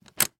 Lever_On.wav